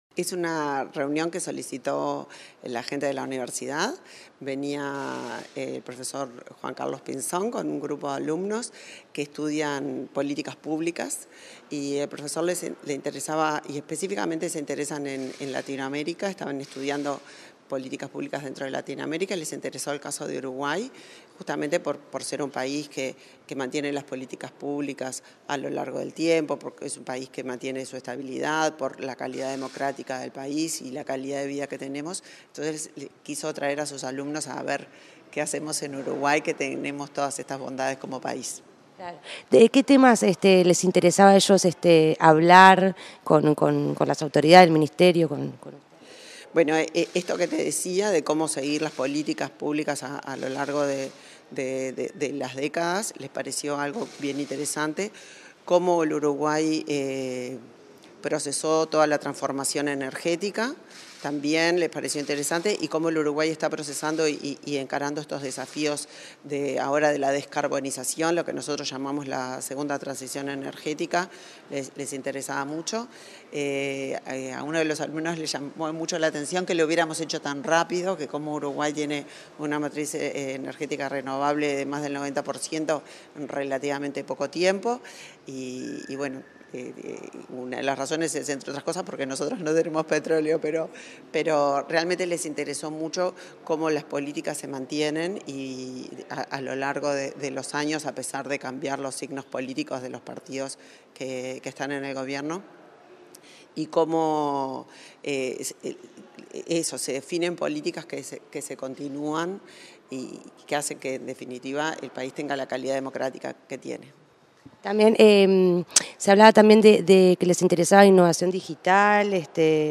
Entrevista a la ministra de Industria, Energía y Minería, Elisa Facio